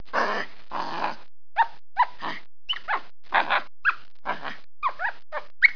دانلود صدای حیوانات جنگلی 76 از ساعد نیوز با لینک مستقیم و کیفیت بالا
جلوه های صوتی